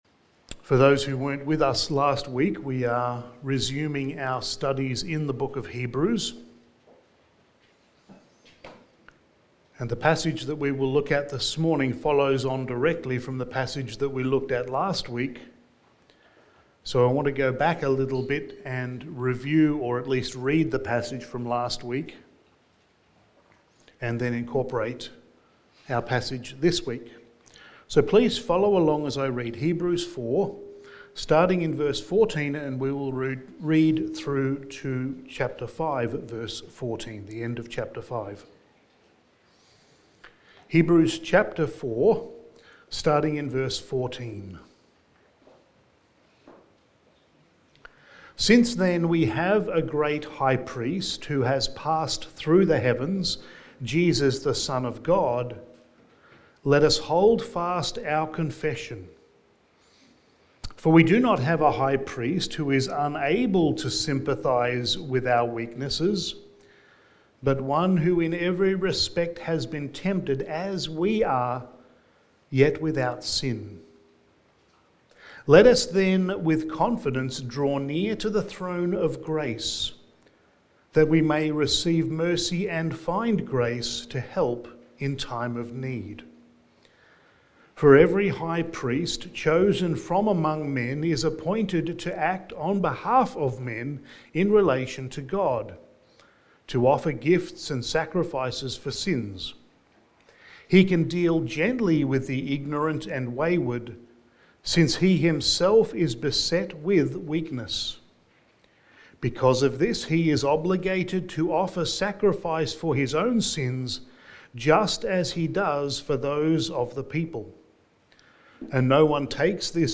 Sermon
Hebrews Series Passage: Hebrews 5:11-14 Service Type: Sunday Morning Sermon 14 « God’s Self-Disclosure Why Do You Speak to Them in Parables?